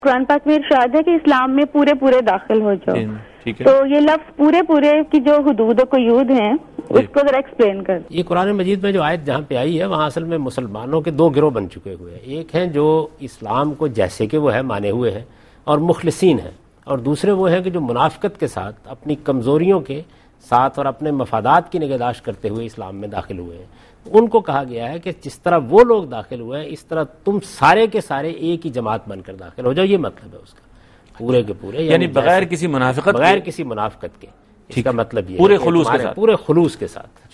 Category: TV Programs / Dunya News / Deen-o-Daanish / Questions_Answers /
Answer to a Question by Javed Ahmad Ghamidi during a talk show "Deen o Danish" on Duny News TV